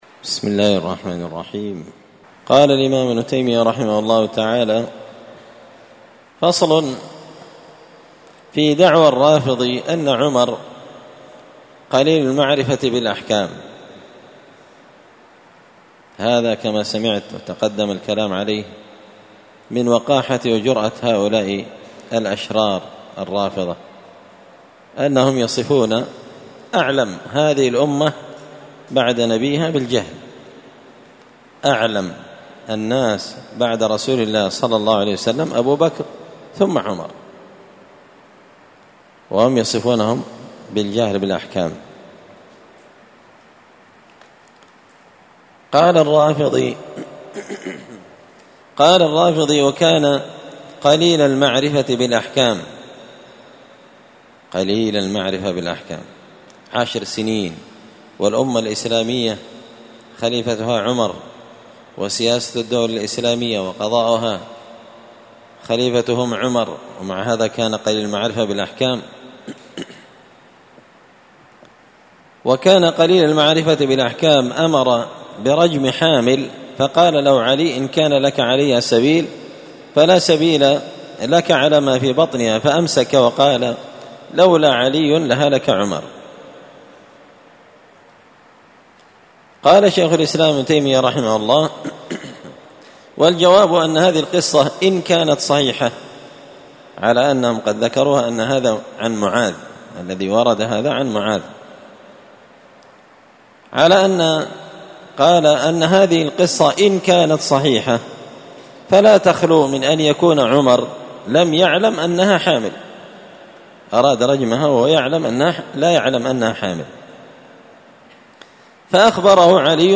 الأربعاء 3 ذو الحجة 1444 هــــ | الدروس، دروس الردود، مختصر منهاج السنة النبوية لشيخ الإسلام ابن تيمية | شارك بتعليقك | 6 المشاهدات
مسجد الفرقان قشن_المهرة_اليمن